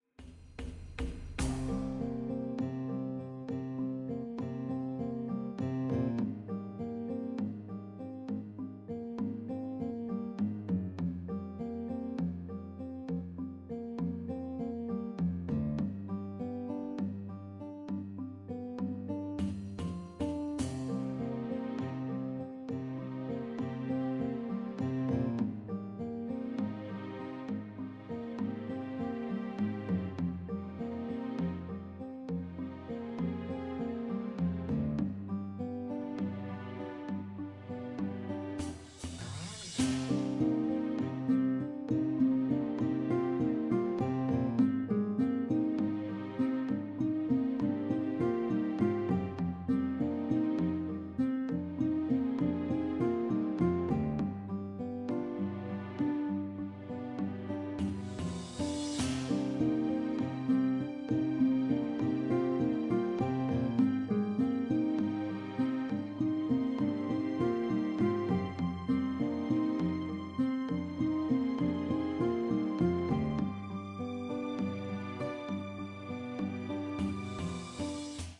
※音量注意のため、音小さめにレンダリングしてあります
廃坑のBGM。落ち着いた雰囲気ですが、これが一番好き！
短めだけどオシャレなメロディーラインと、アコギの音色も好き(*･ω･)